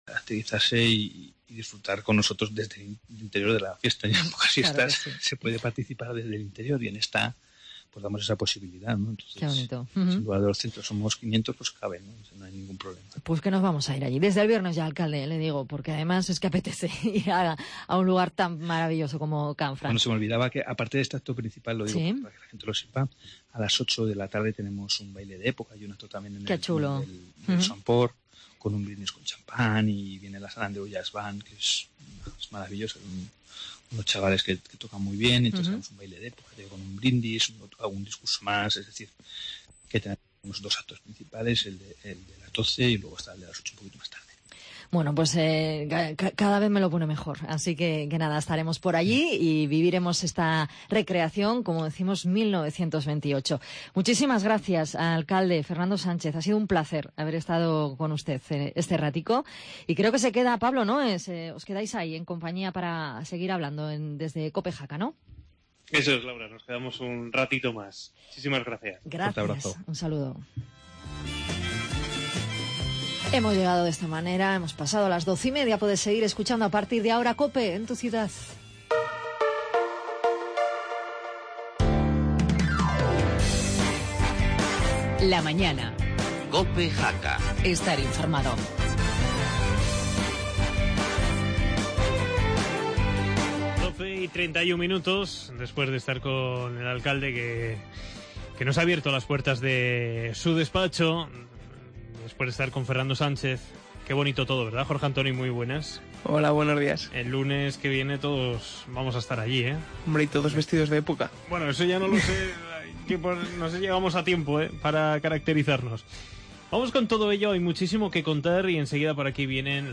AUDIO: Última hora y tertulia política municipal de Jaca.